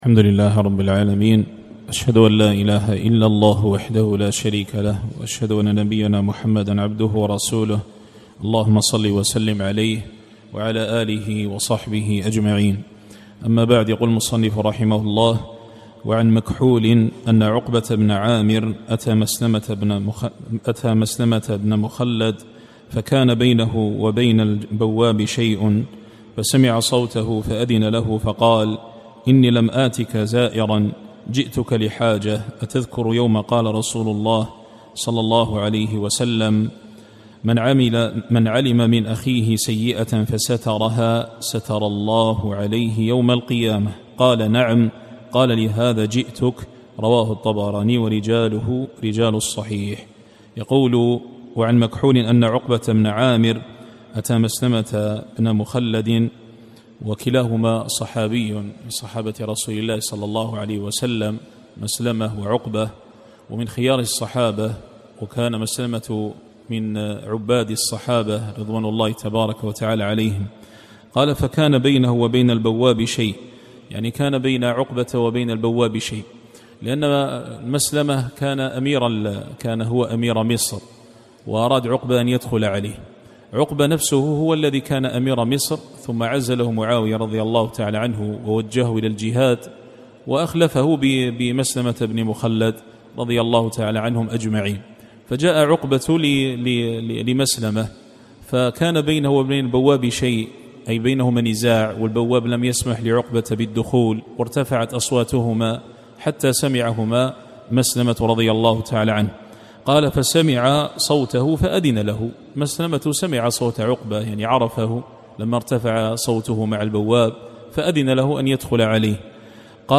الدرس الواحد والعشرون-21-